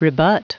Prononciation du mot rebut en anglais (fichier audio)
Prononciation du mot : rebut